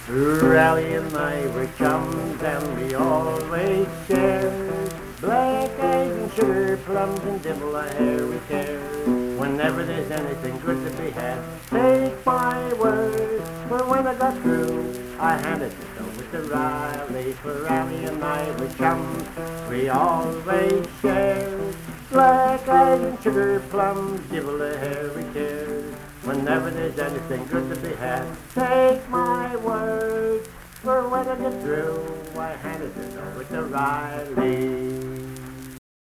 Accompanied vocal and guitar music
Verse-refrain 1(4). Performed in Hundred, Wetzel County, WV.
Ethnic Songs
Voice (sung), Guitar